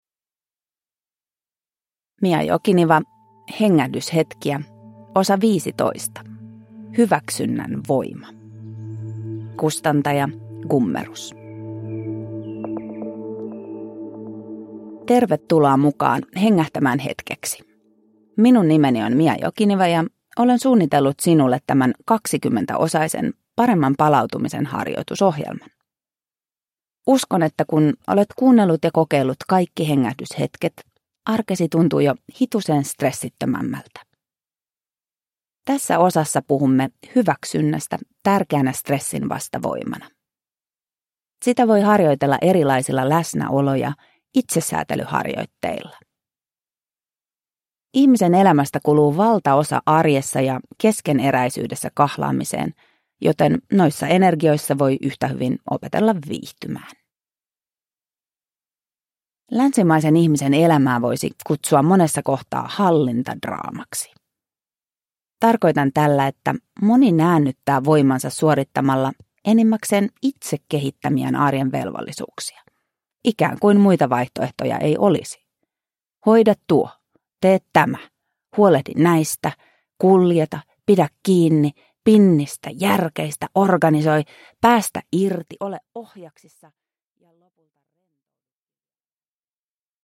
Hengähdyshetkiä (ljudbok) av Mia Jokiniva